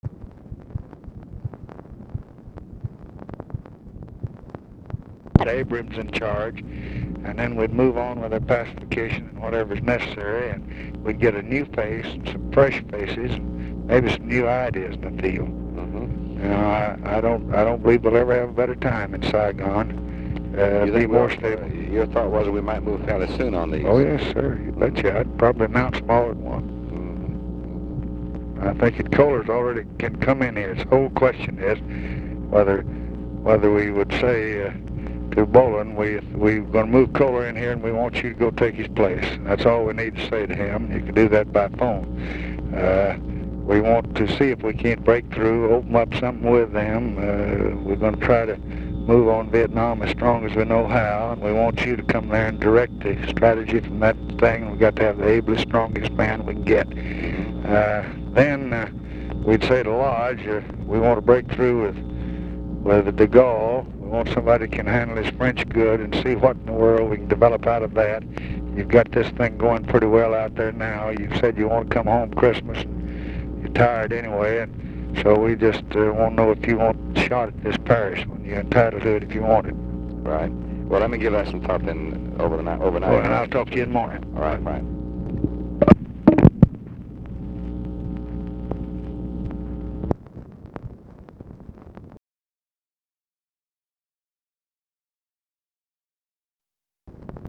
Conversation with DEAN RUSK, September 17, 1966
Secret White House Tapes